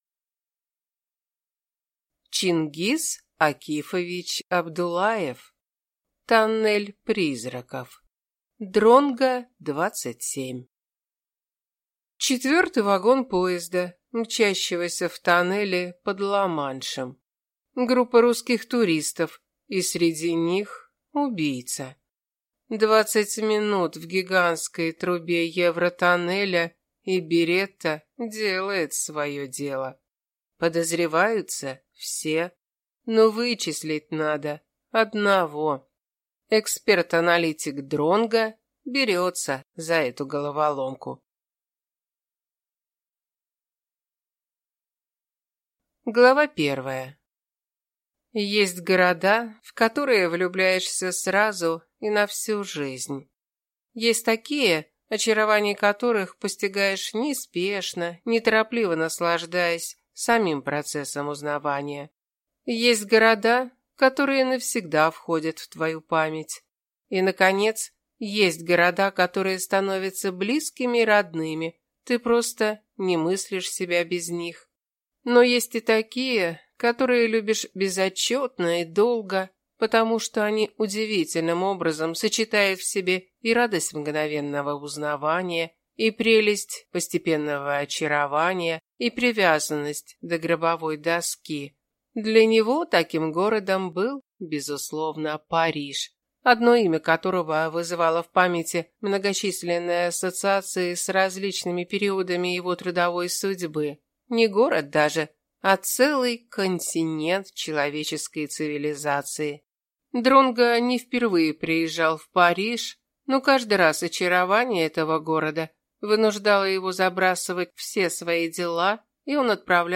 Аудиокнига Тоннель призраков | Библиотека аудиокниг